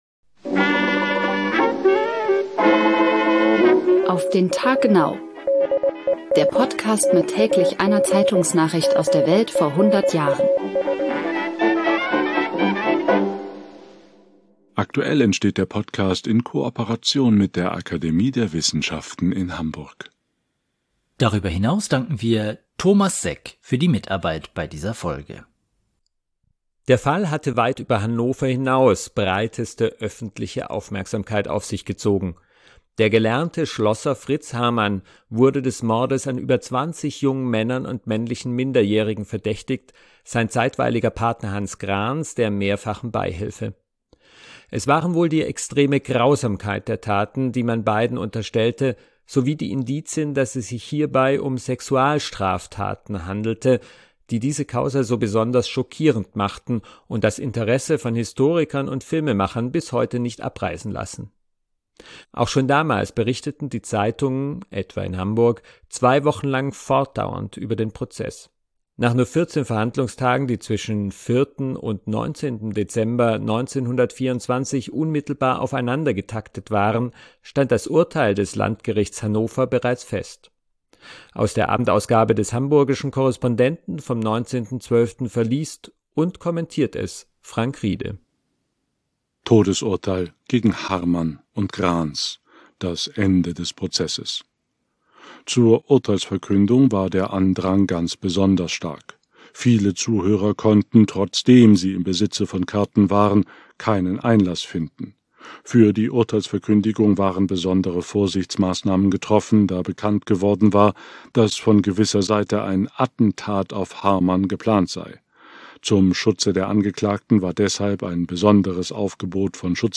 verliest und kommentiert es